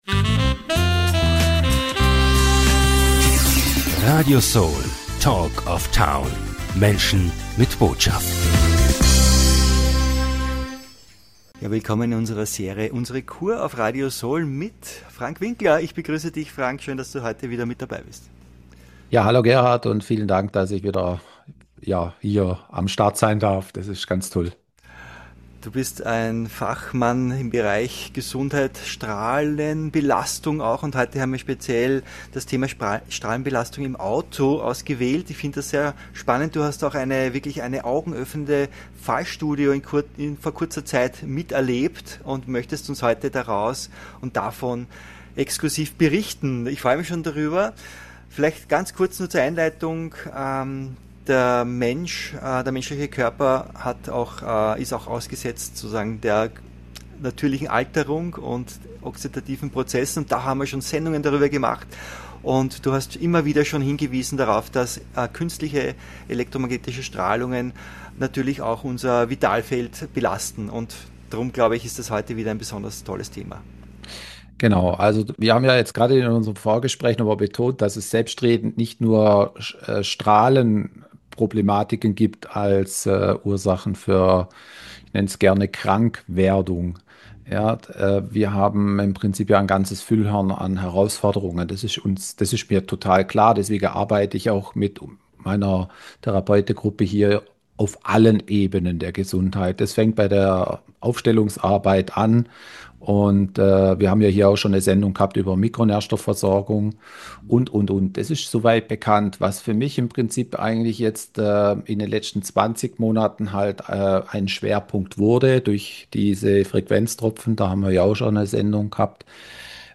In diesem Interview spricht er über die Strahlenbelastung im Auto.